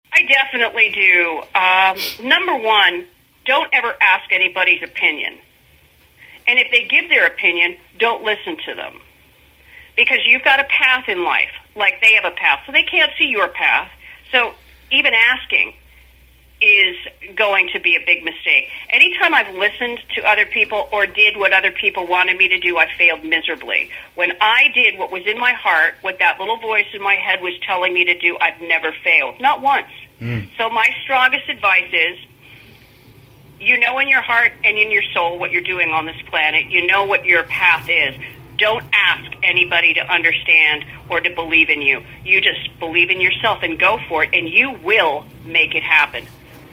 IN 2016, near the end of an illuminating interview with ex-Runaway Cherie Currie, we decided to ask her a deep and meaningful question.